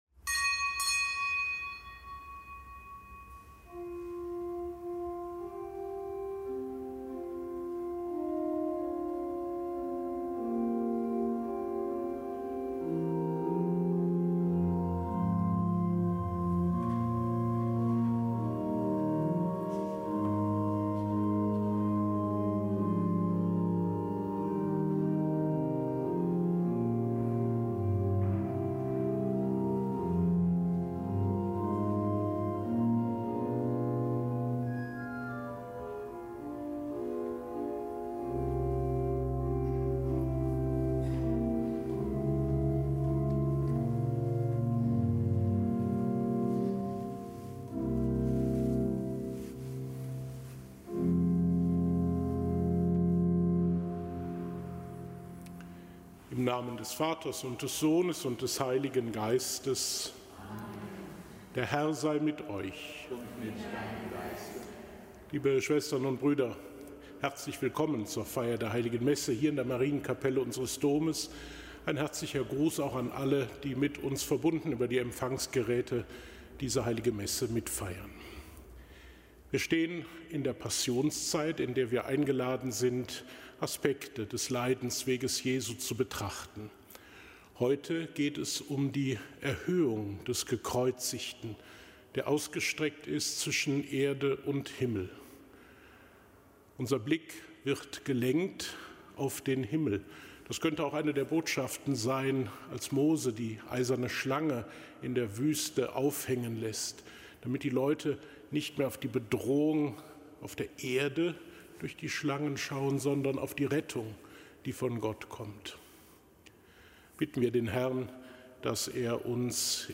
Kapitelsmesse aus dem Kölner Dom am Dienstag der fünften Fastenwoche.